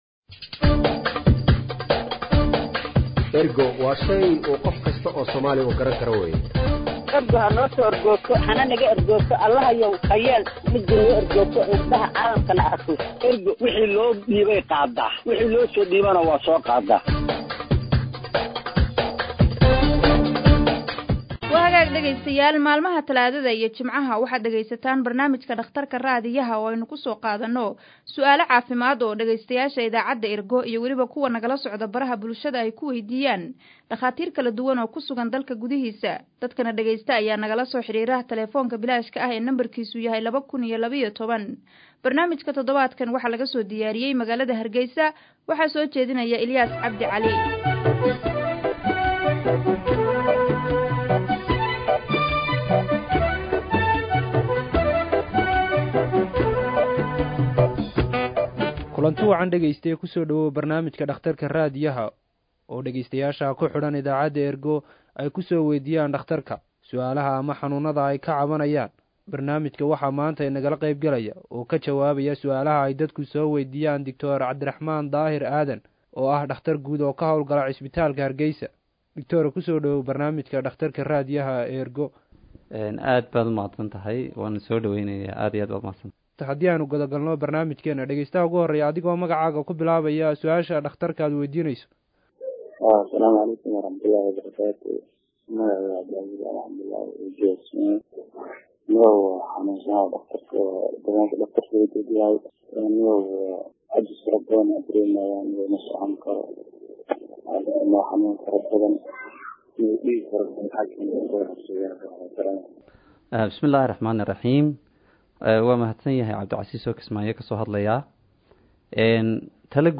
Waa baraamij ay dhegeystayaasha idaacadda Ergo ay su’aalo caafimaad ku soo waydiiyaan dhaqaatiir kala duwan oo jooga dalka gudihiisa.